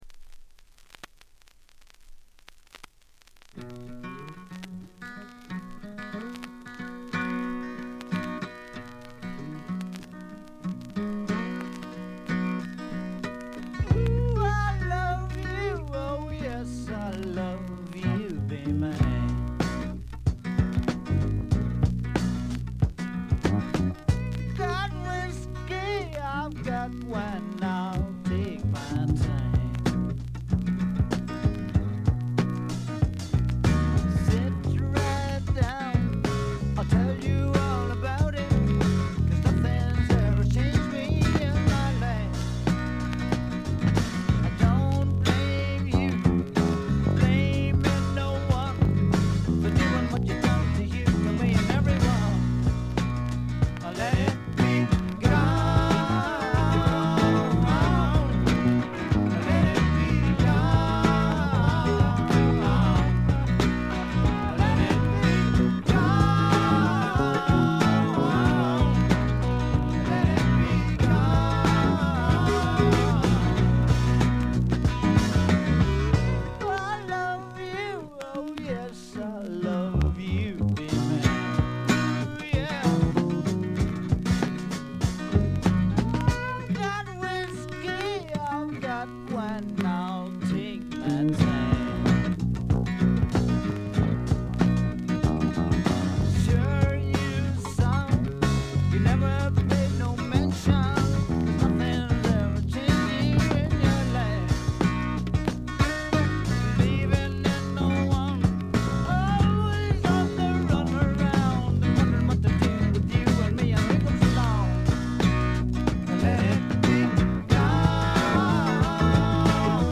バックグラウンドノイズ、チリプチ、プツ音等多め大きめですが、鑑賞を妨げるほどではなく普通に聴けるレベルと思います。
内容は笑っちゃうぐらい売れなさそうな激渋スワンプ。
試聴曲は現品からの取り込み音源です。